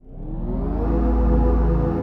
heatShieldsOn.wav